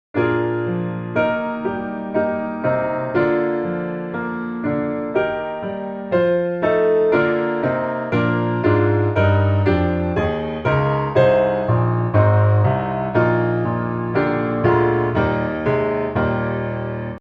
Piano Hymns
Eb Major